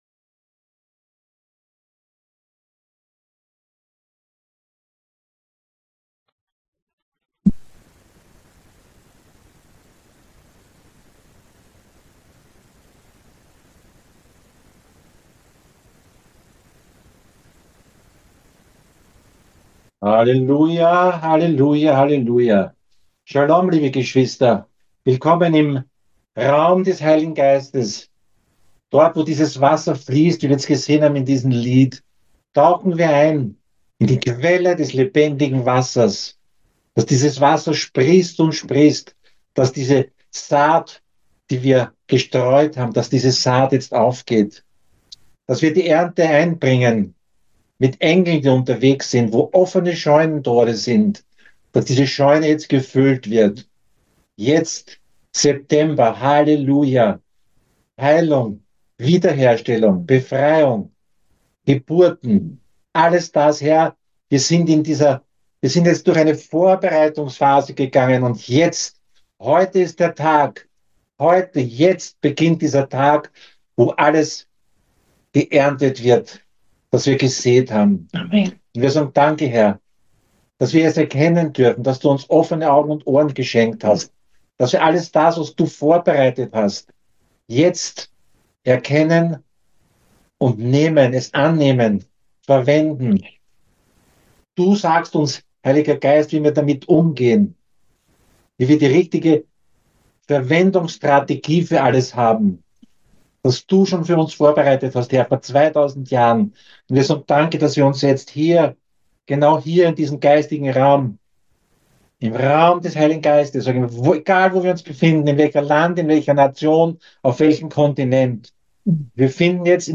Gottesdienstaufnahmen/2024/9.